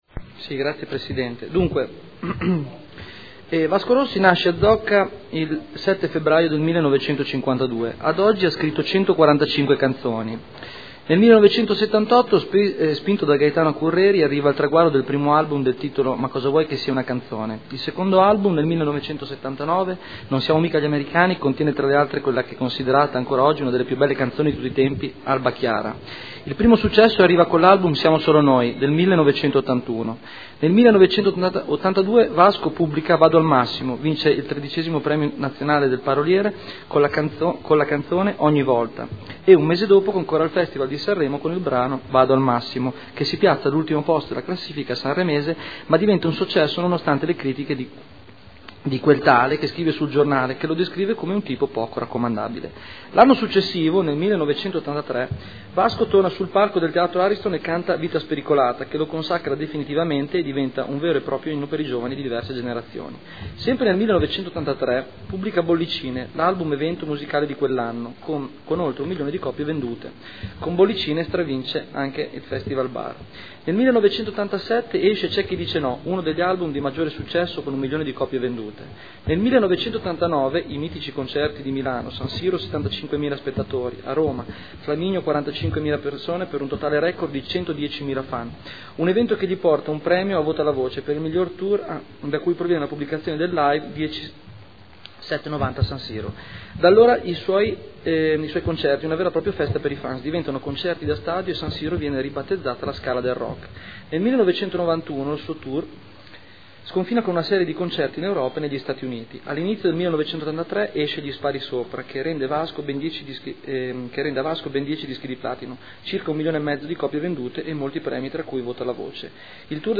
Seduta del 21/01/2013. Ordine del Giorno presentato dal consigliere Barberini (Lega Nord) avente per oggetto: “Vasco Rossi day”